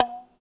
Pluck.wav